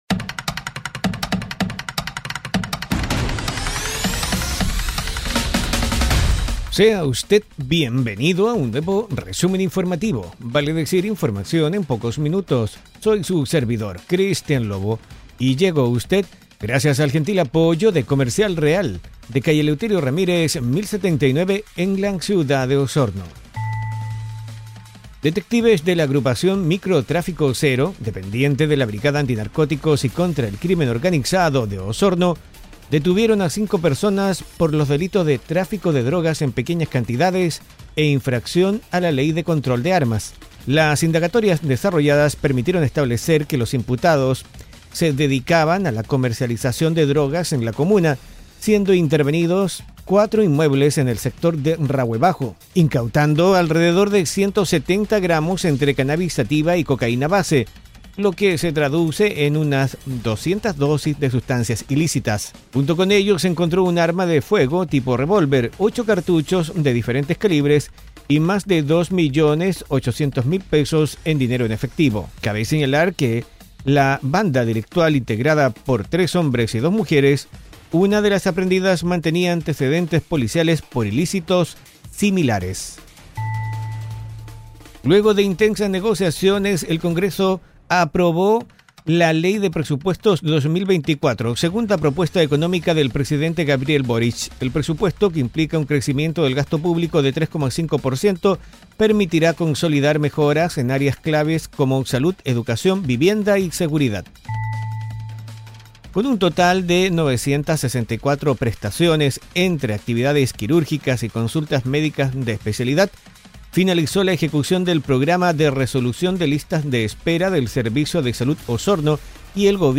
Este audio podcast te trae un resumen rápido y conciso de una decena de noticias enfocadas en la Región de Los Lagos.